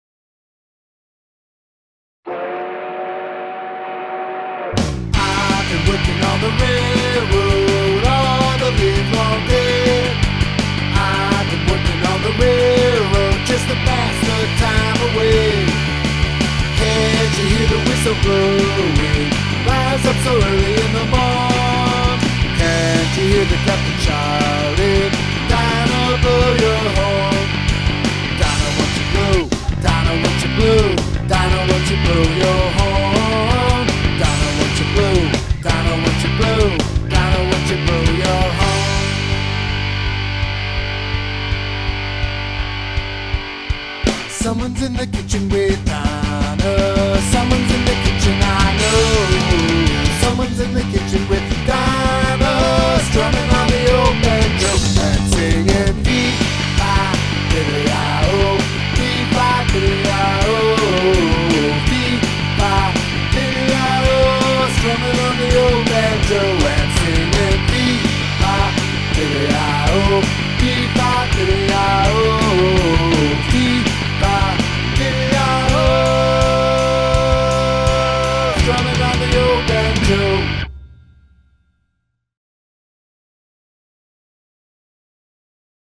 alone...Lots of short, fun, loud songs ... perfect for your